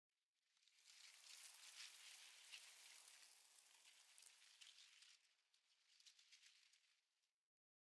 bushrustle2.ogg